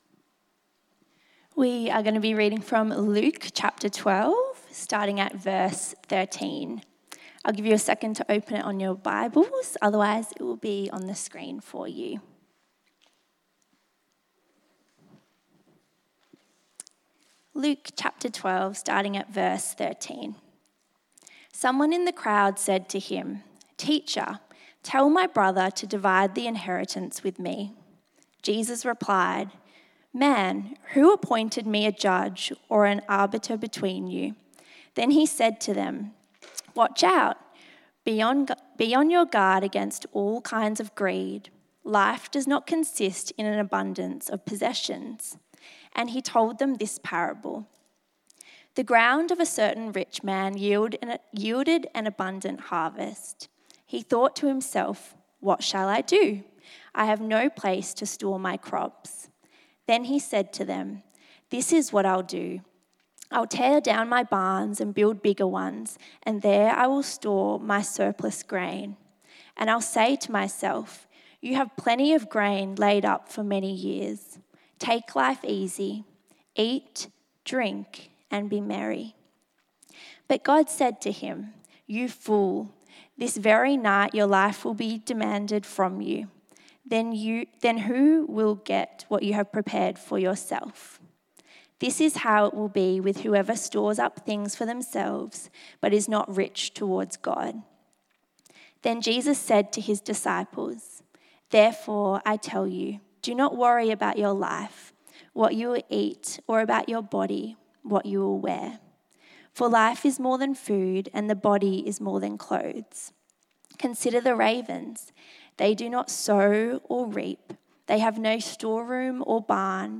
Service Type: 6PM